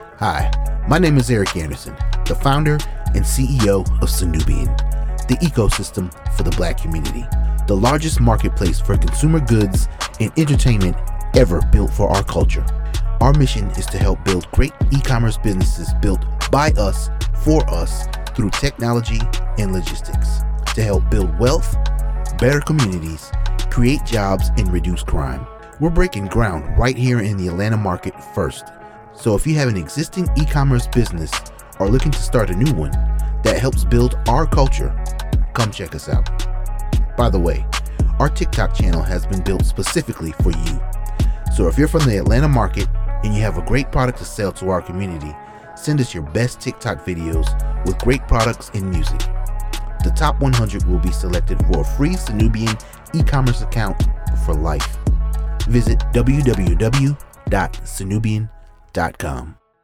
Radio-Commercial-Atlanta-WAV.wav